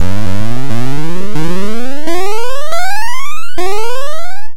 描述：任天堂娱乐系统风格的哔哔声在MaxMSP中生成
Tag: 哔哔声 芯片 计算机 电子 游戏 LOFI 任天堂 复古 合成